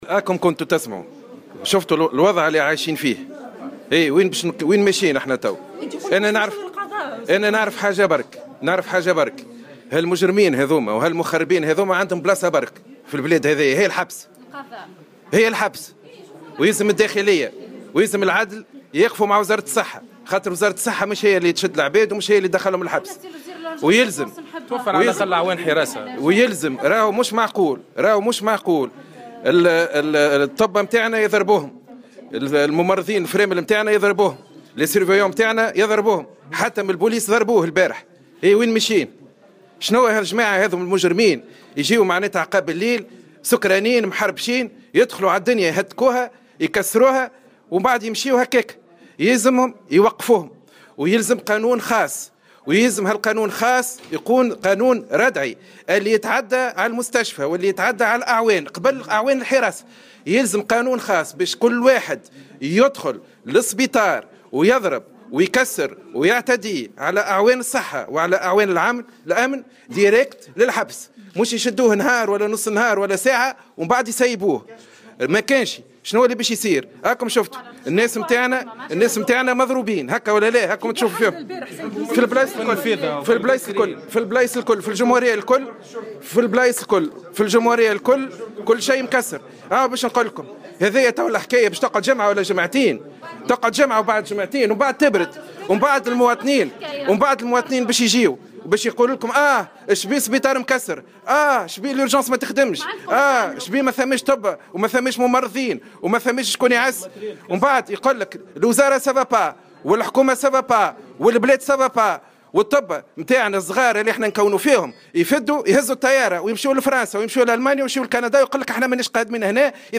Le ministre de la Santé, Slim Chaker a affirmé, lors de sa visite à l'hôpital universitaire de Sahloul à Sousse que la place des criminels qui ont agressé hier le cadre médical et paramédical de l'hôpital est la prison ferme.